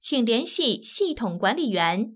ivr-contact_system_administrator.wav